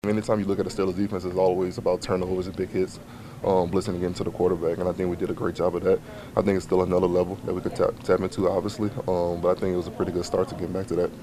Linebacker Patrick Queen says the Steelers’ defense is only beginning to show what it can do.